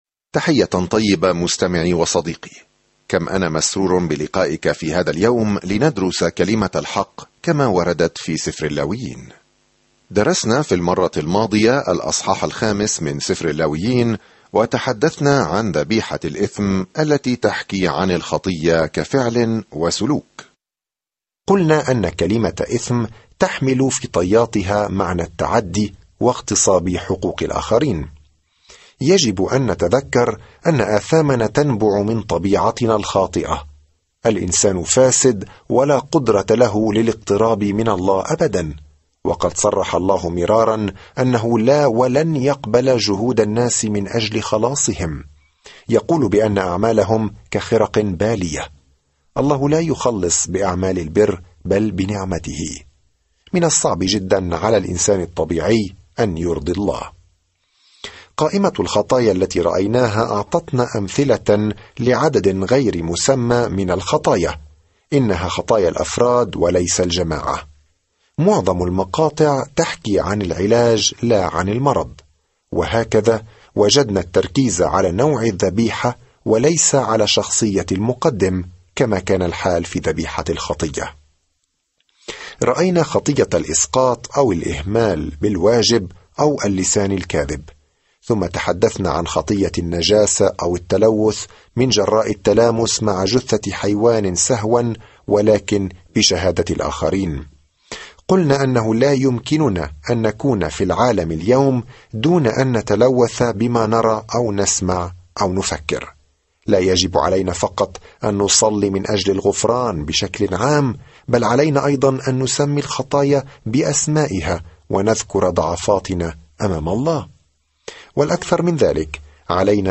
في العبادة والتضحية والتبجيل، يجيب سفر اللاويين على هذا السؤال بالنسبة لإسرائيل القديمة. سافر يوميًا عبر سفر اللاويين وأنت تستمع إلى الدراسة الصوتية وتقرأ آيات مختارة من كلمة الله.